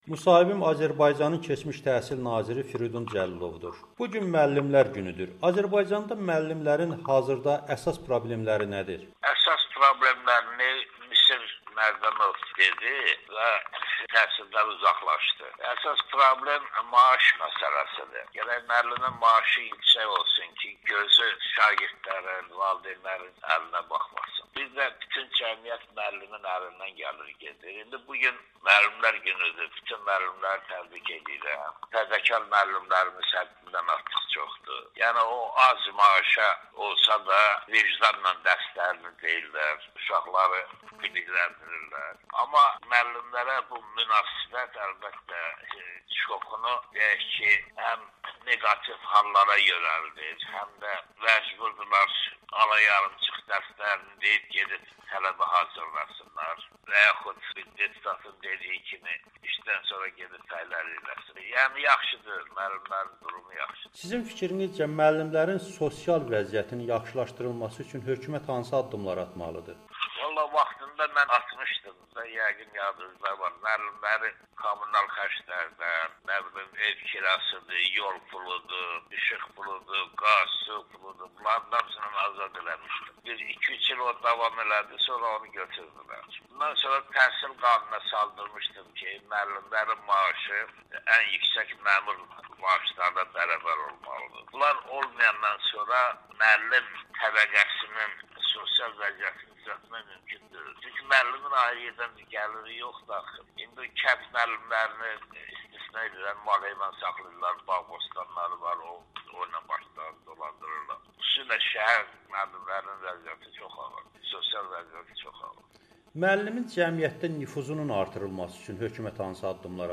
Azərbaycanın keçmiş təhsil naziri Firudin Cəlilovun Amerikanın Səsinə müsahibəsi